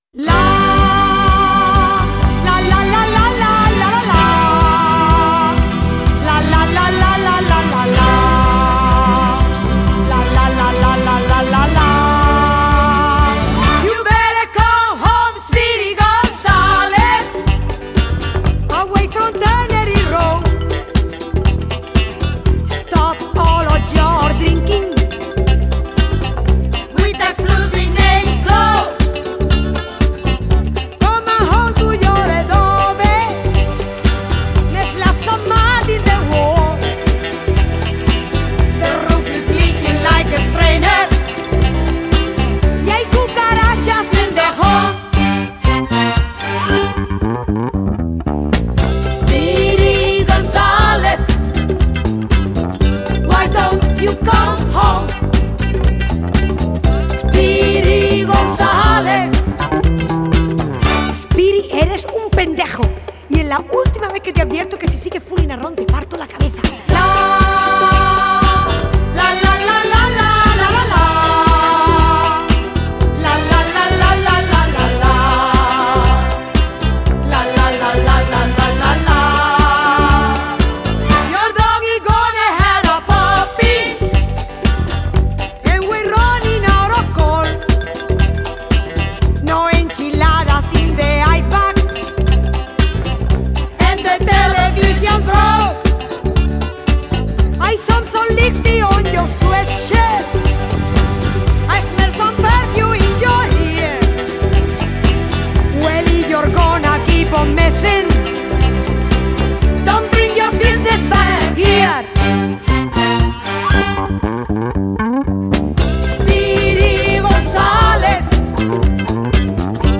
Genre: Funk / Soul, Disco